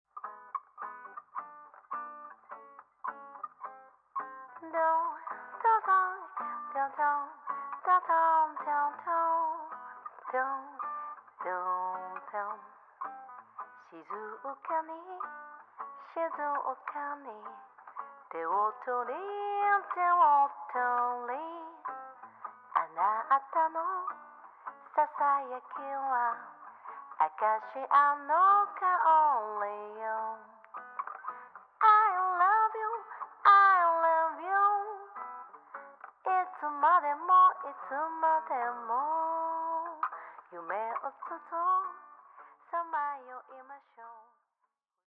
guitar / vocal
bass
piano / rhodes / melodica